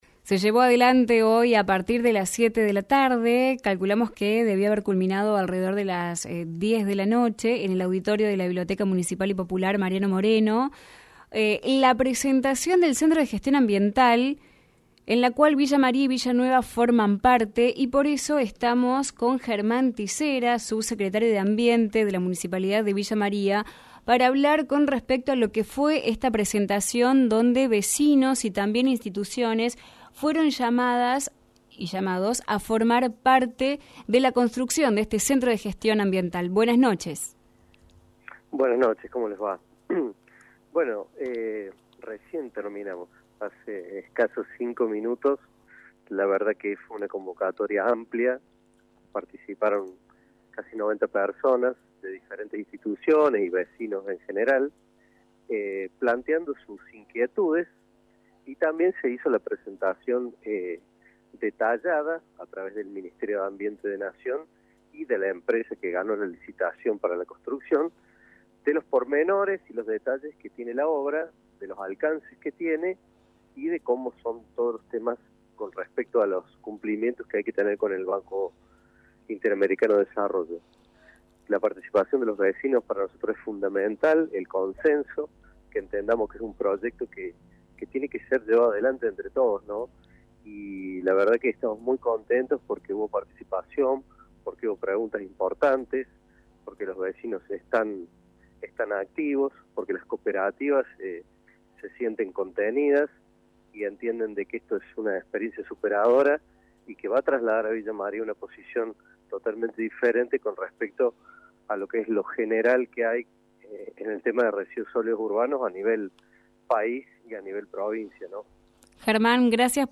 Germán Tissera, Sub Secretario de Ambiente de la Municipalidad de Villa María, al terminar la presentación del proyecto del Centro de Gestión Ambiental Villa María-Villa Nueva, en la Medioteca, en comunicación telefónica con «No Es Lo Que Parece», resaltó la importancia de la iniciativa para el conglomerado y de haber contado con la participación de alrededor de 90 vecinos que evacuaron dudas e hicieron sus aportes.